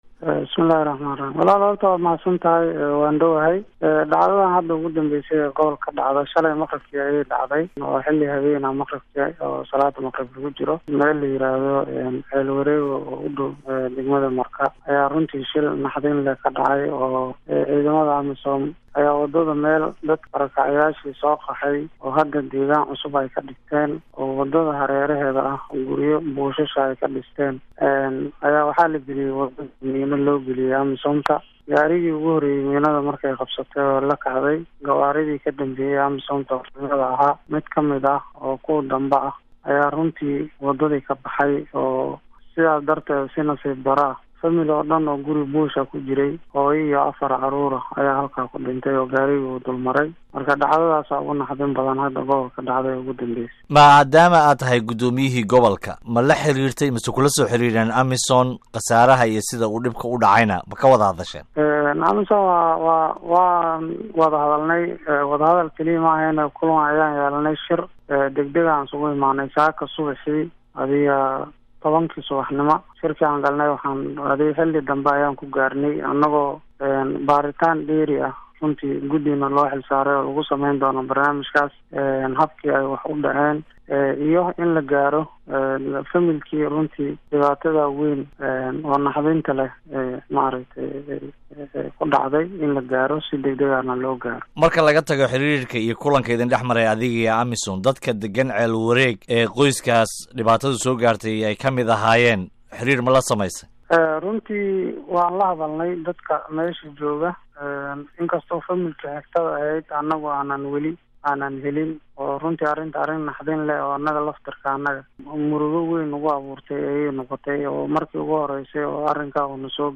Dhegayso: Waraysiga Guddoomiyaha Gobolka Shabeelada Hoose